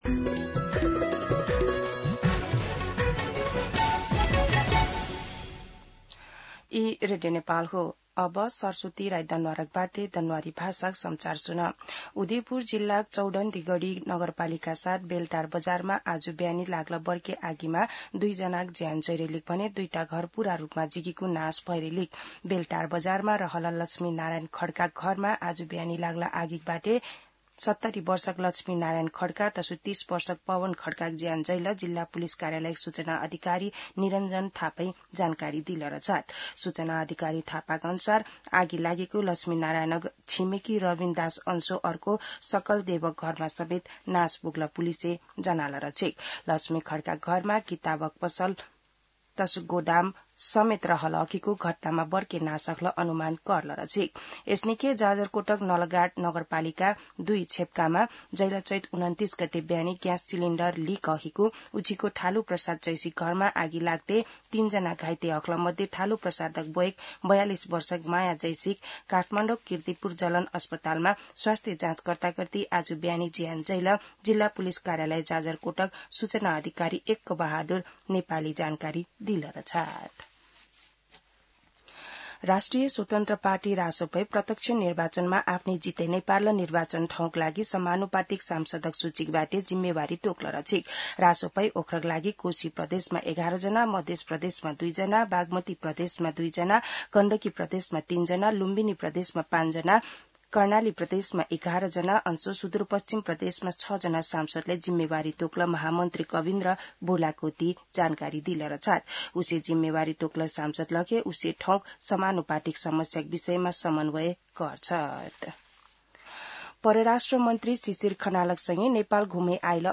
दनुवार भाषामा समाचार : ८ वैशाख , २०८३
Danuwar-News-08.mp3